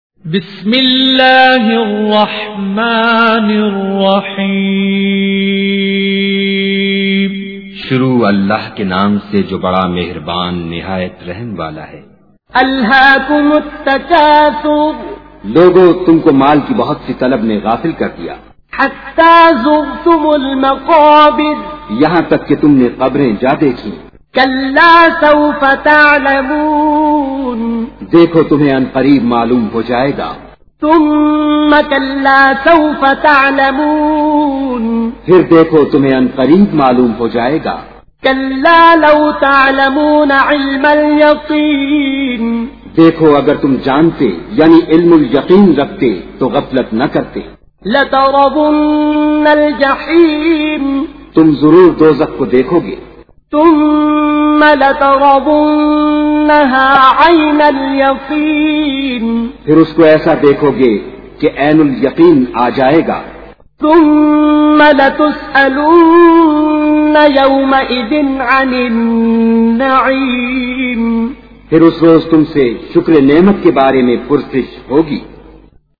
تلاوت بااردو ترجمہ